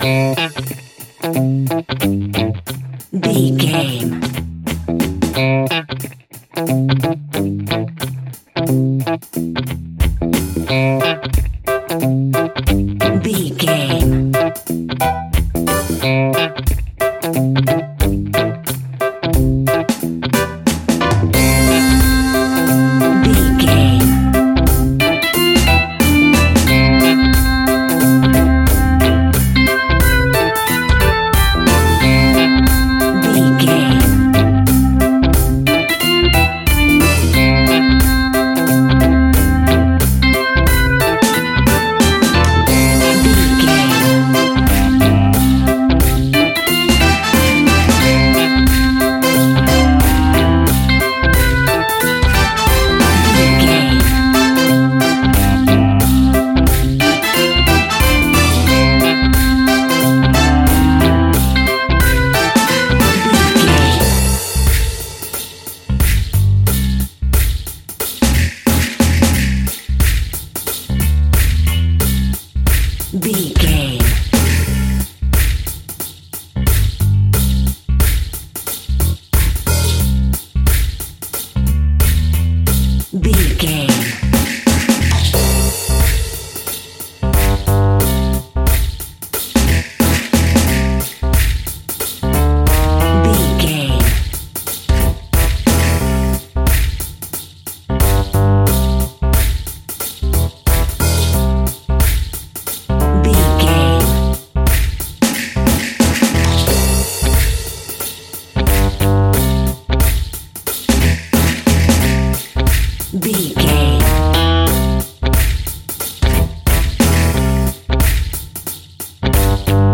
Take me back to the old skool retro seventies reggae sounds!
Ionian/Major
reggae instrumentals
laid back
chilled
off beat
drums
skank guitar
hammond organ
transistor guitar
percussion
horns